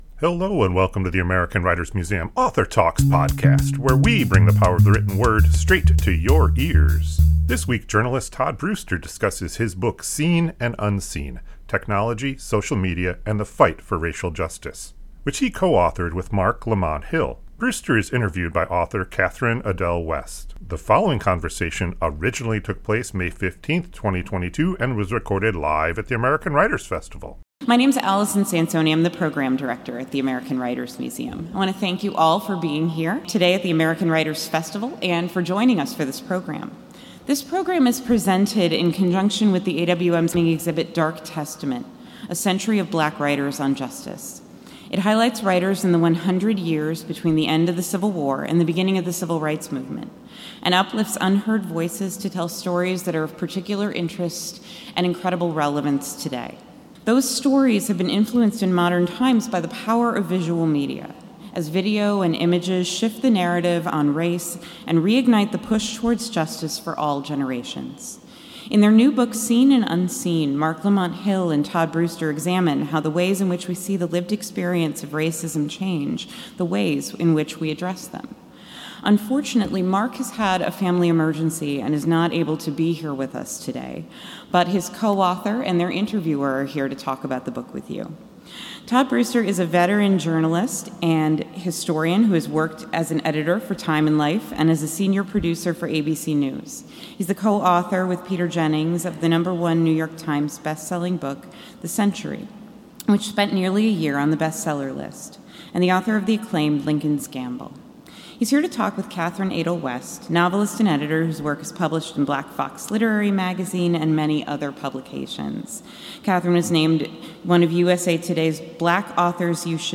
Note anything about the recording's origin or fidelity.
This conversation originally took place May 15, 2022 and was recorded live at the American Writers Festival.